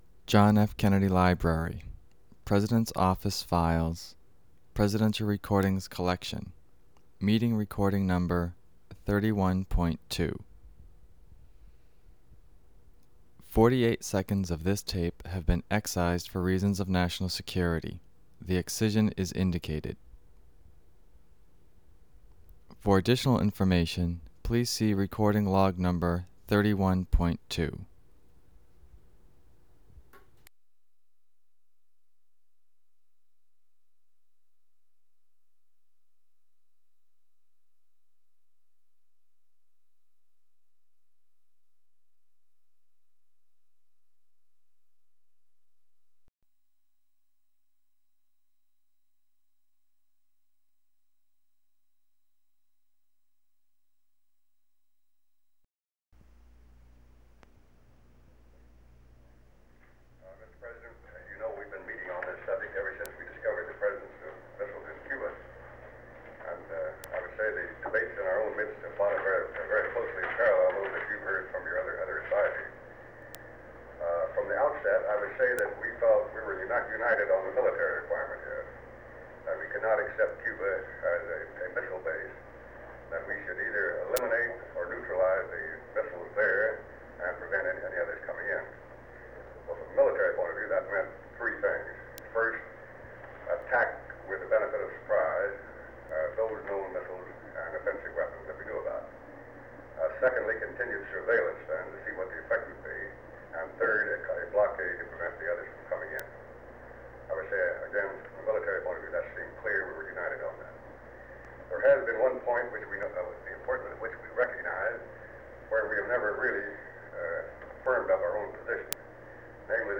Meeting with the Joint Chiefs of Staff on the Cuban Missile Crisis
Secret White House Tapes | John F. Kennedy Presidency Meeting with the Joint Chiefs of Staff on the Cuban Missile Crisis Rewind 10 seconds Play/Pause Fast-forward 10 seconds 0:00 Download audio Previous Meetings: Tape 121/A57.